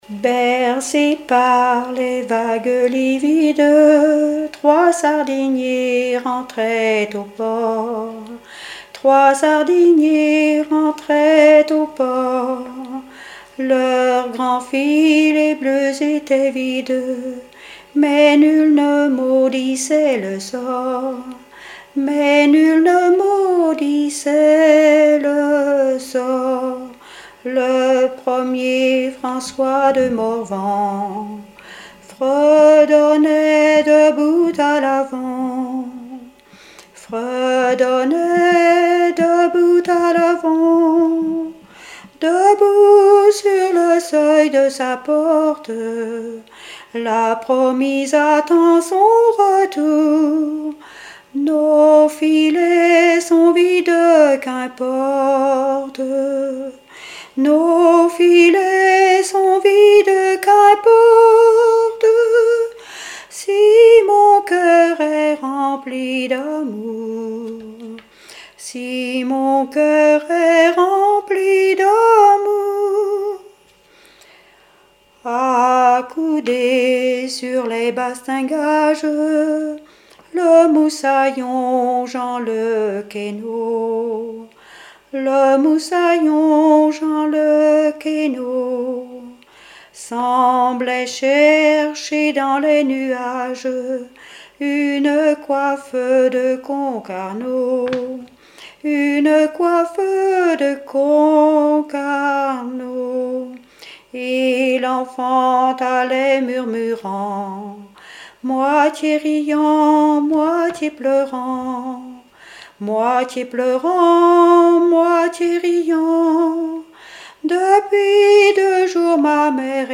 Genre strophique
Deux chansons maritimes
Pièce musicale inédite